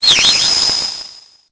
Cri_0856_EB.ogg